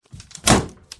Free SFX sound effect: Wood Crack.
Wood Crack
348_wood_crack.mp3